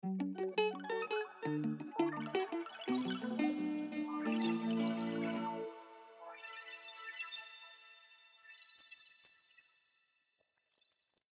• Taps＋Portal＋その他ノードを使用
このように、Tapsの多彩なディレイパターン、Portalsの複雑なサウンド、そして両者と他ノードを組み合わせた予測不可能なサウンド変化を構築することが可能です。